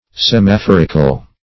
Search Result for " semaphorical" : The Collaborative International Dictionary of English v.0.48: Semaphoric \Sem`a*phor"ic\, Semaphorical \Sem`a*phor"ic*al\a. [Cf. F. s['e]maphorique.] Of or pertaining to a semaphore, or semaphores; telegraphic.